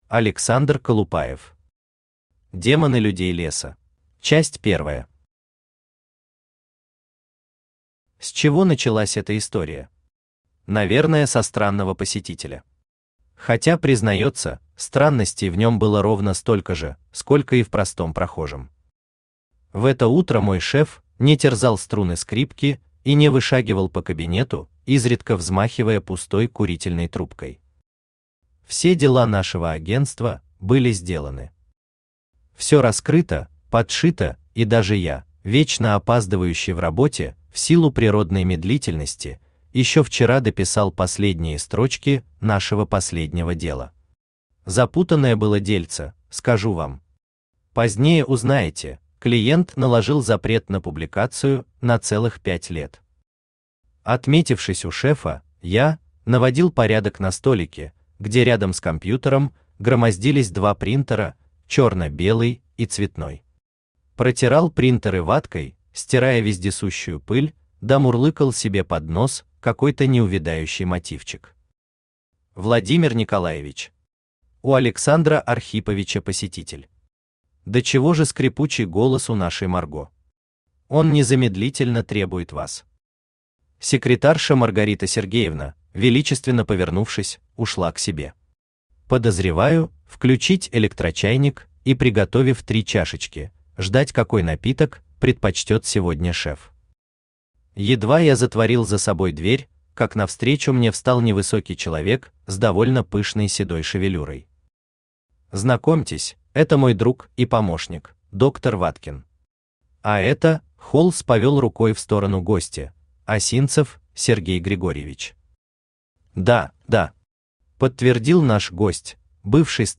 Аудиокнига Демоны Людей Леса | Библиотека аудиокниг
Aудиокнига Демоны Людей Леса Автор Александр Алексеевич Колупаев Читает аудиокнигу Авточтец ЛитРес.